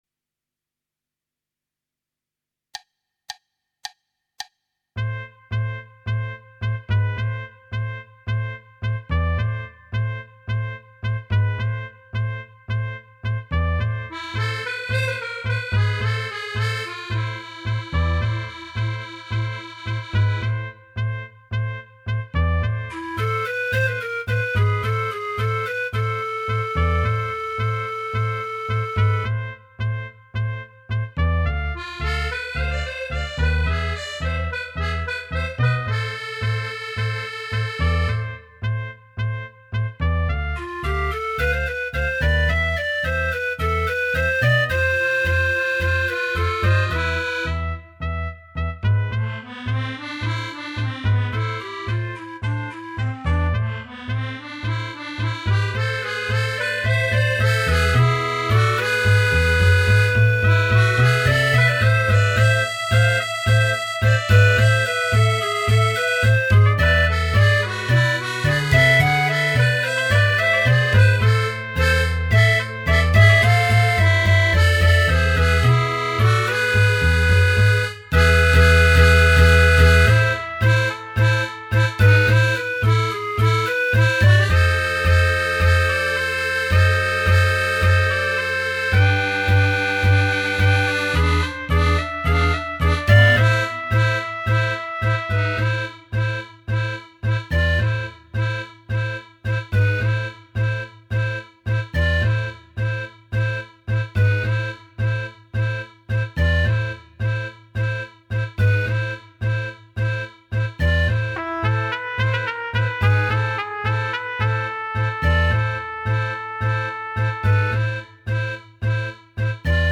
Right click to download Tango minus Instrument 2